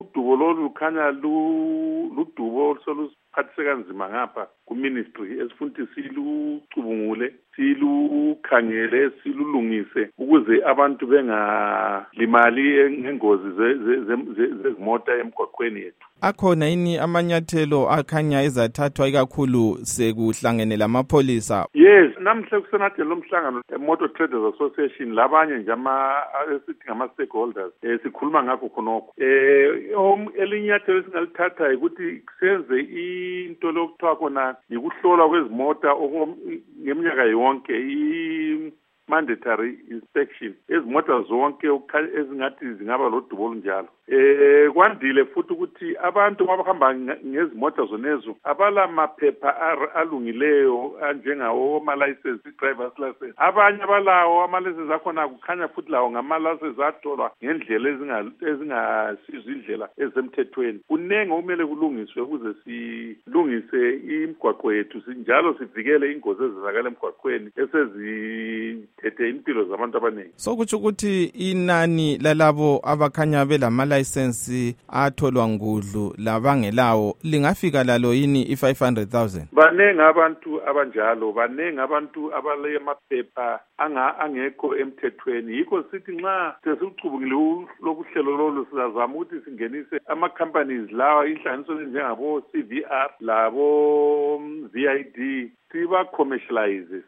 Ingxoxo loMnu. Obert Mpofu